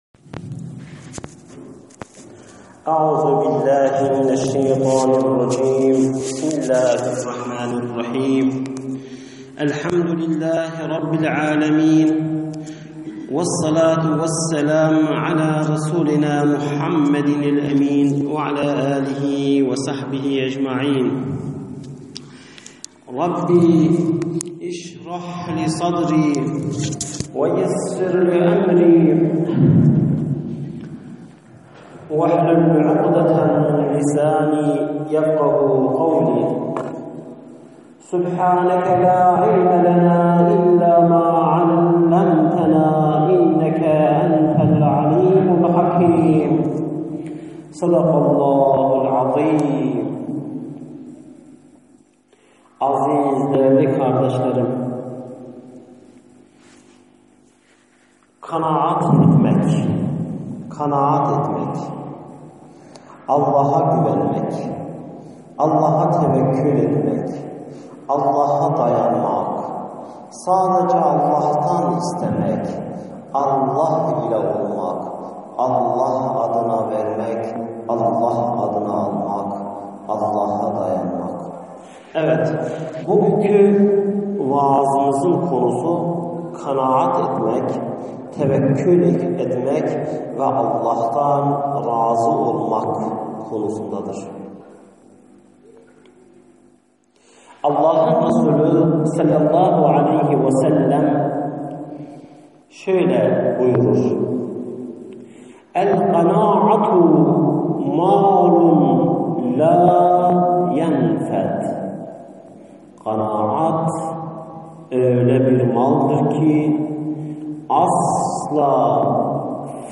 SESLİ SOHBETLER